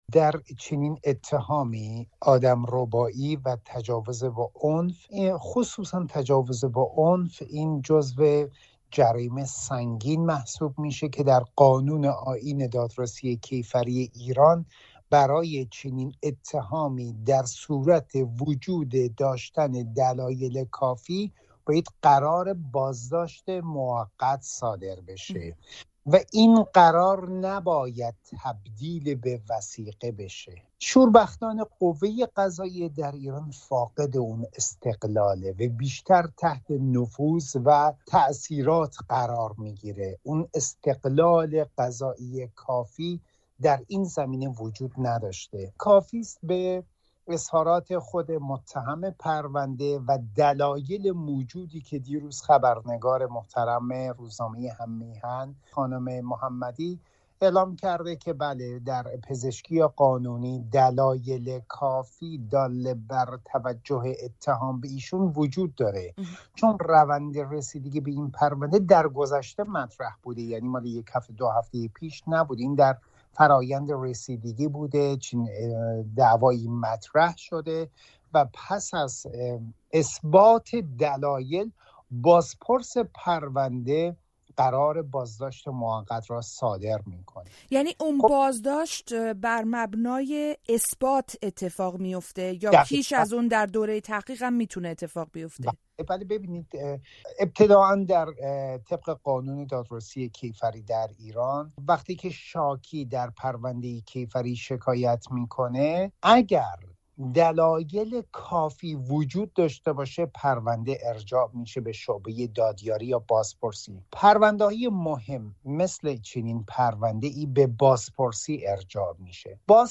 پرسش‌ها و پاسخ‌ها به اتهام وارد شده به پژمان جمشیدی در گفت‌وگو با یک حقوقدان